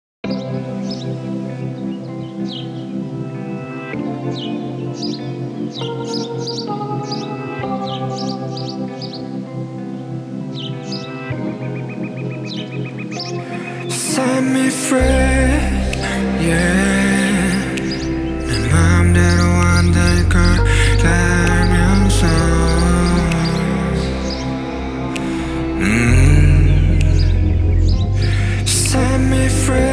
Genre: K-Pop